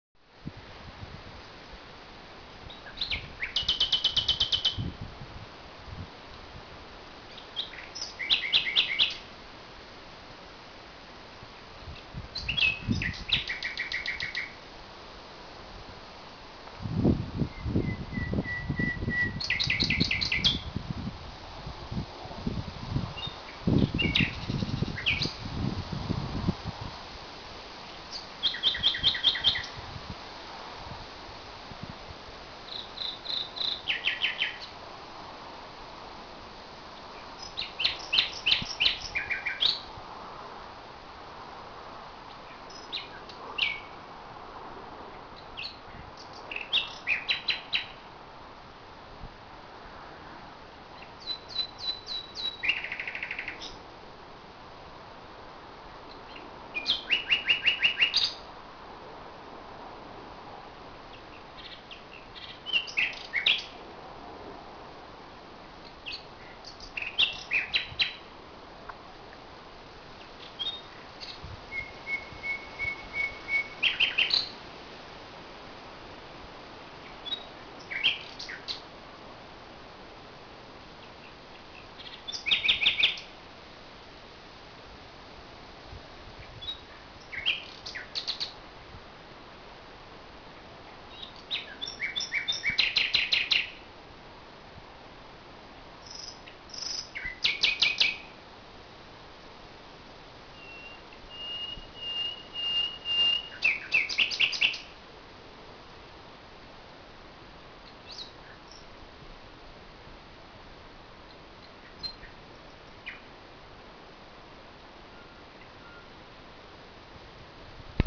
Nachtigall - S��er n�chtlicher "Terror"
Im Garten hat eine Nachtigall ihr Lager aufgeschlagen und sucht nach einem Partner. Das Repertoire dieses unscheinbaren Wesen ist unglaublich. Gestern Nacht bin ich dann in der Dunkelheit um 00:30 Uhr durch die Brombeeren gerobbt und habe die Aufnahmefunktion meiner Kamera genutzt.